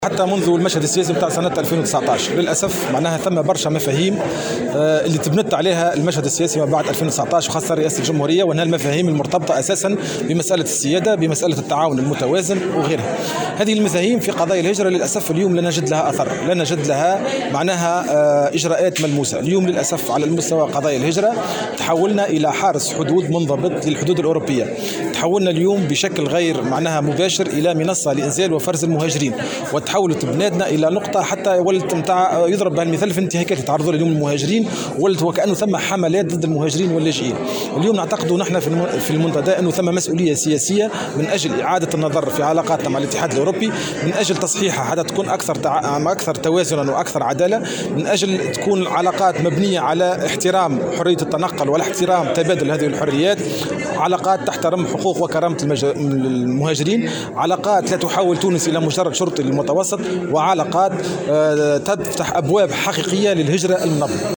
وأضاف في تصريح اليوم لمراسل "الجوهرة أف أم" على هامش الاحتفال بالذكرى العاشرة لتأسيس المنتدى، أن المسؤولية سياسية وأنه لابد من إعادة النظر في العلاقات مع الاتحاد الاوروبي لتكون مبنية على احترام حرية التنقل واحترام حقوق المهاجرين وكرامتهم وتفتح أبوابا حقيقية للهجرة المنظمة".